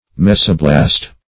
Mesoblast \Mes"o*blast\, n. [Meso- + -blast.] (Biol.)